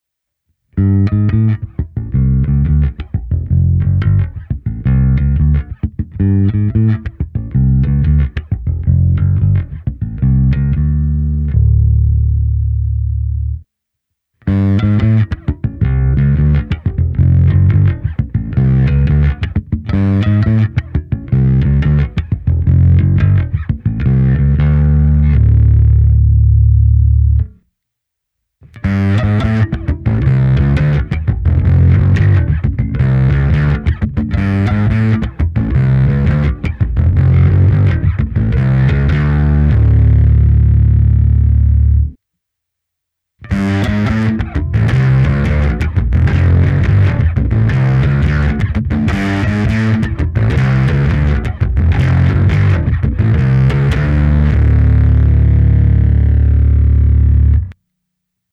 Zkreslení má rovněž výrazně elektronkový vintage charakter. Nahrál jsem ukázky s baskytarou Fender American Professional II Precision Bass V s roundwound niklovými strunami Sadowsky Blue Label v dobrém stavu. V nahrávkách jsem použil vždy kompresor, lehkou ekvalizaci a simulaci aparátu snímaného kombinací linky a mikrofonu. V první části je jen zvuk baskytary bez zařazení pedálu, pak navazují tři ukázky postupně rostoucího zkreslení.
Zde u extrémně jednoduché krabičky nic takového neslyším, basový základ je stále mohutný.